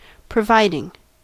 Ääntäminen
Synonyymit provided Ääntäminen US Haettu sana löytyi näillä lähdekielillä: englanti Käännöksiä ei löytynyt valitulle kohdekielelle.